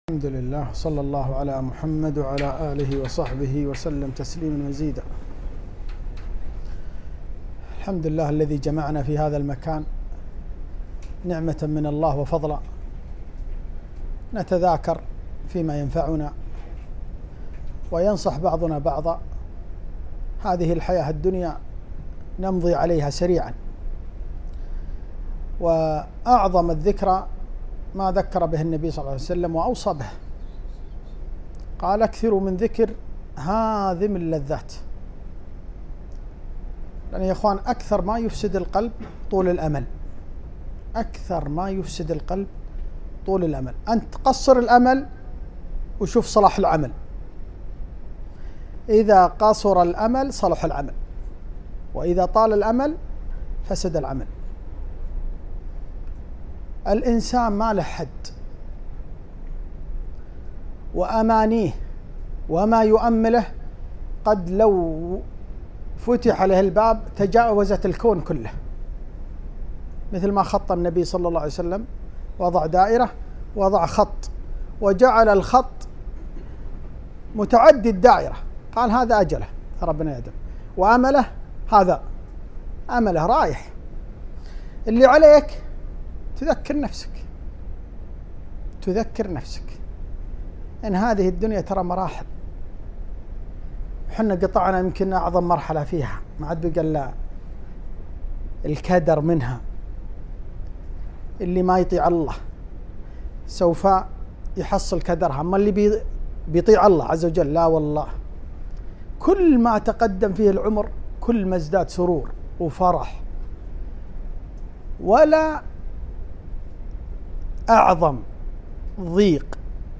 موعظة بليغة [ حـقـيـقـة هـذه الـحـيـاة الـدنـيـا ]